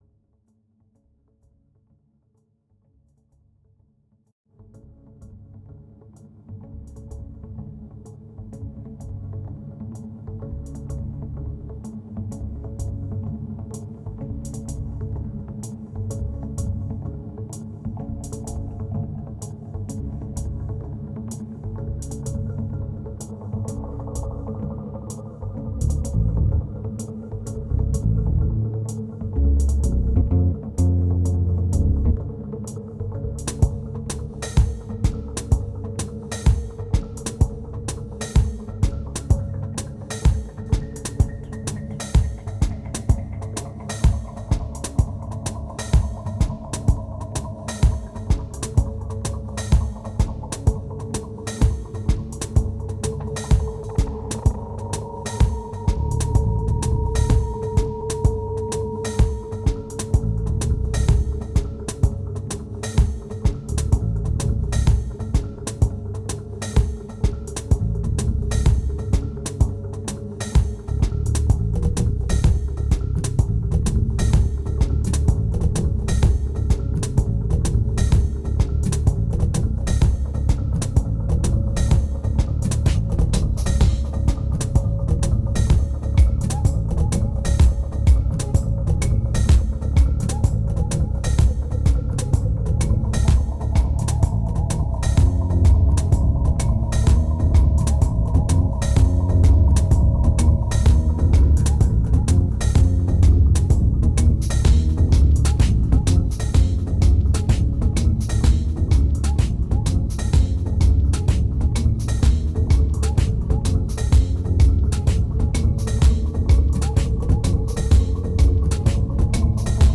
1999📈 - -26%🤔 - 127BPM🔊 - 2010-07-28📅 - -245🌟